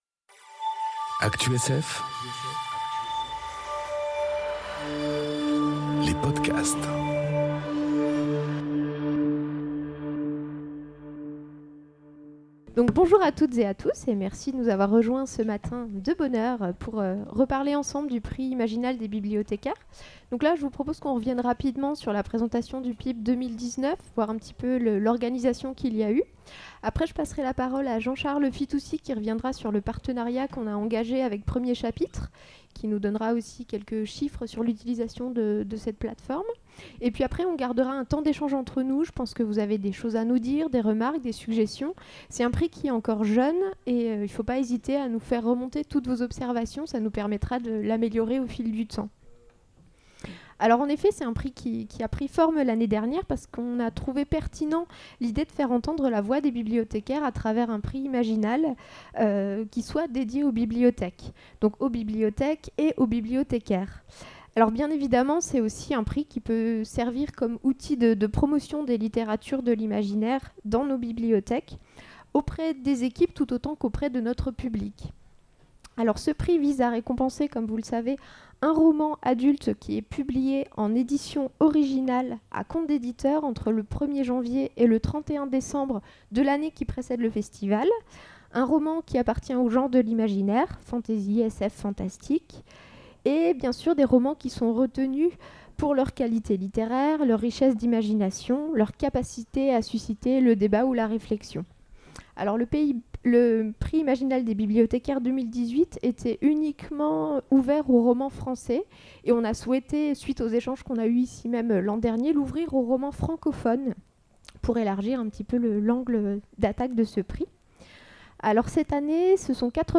Imaginales 2019 : Rencontre professionnelle bibliothèques et médiathèques